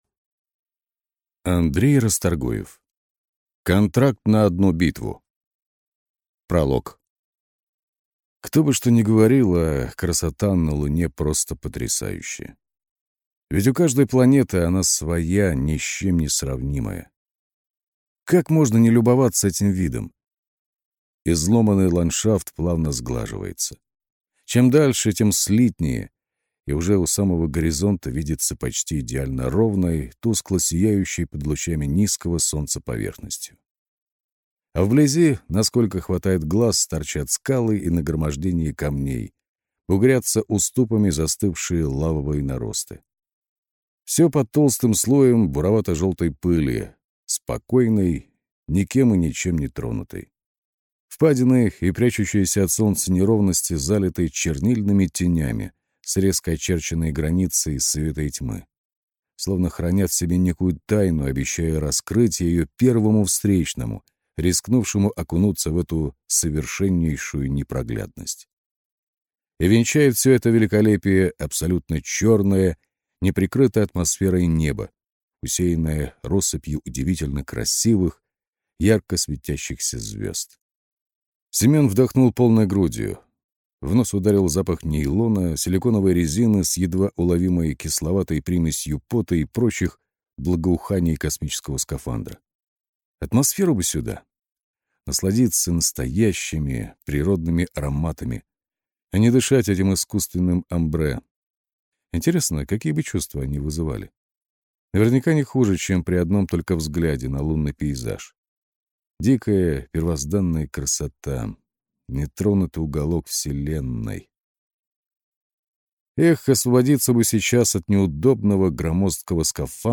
Аудиокнига Контракт на одну битву | Библиотека аудиокниг